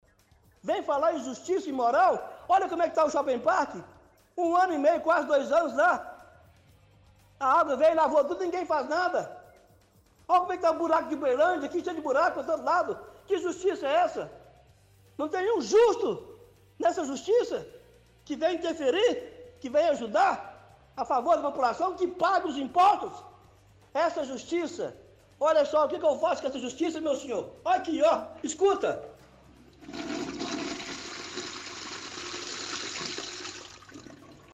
Repórter diz não ter tempo para comentar a fala do ouvinte.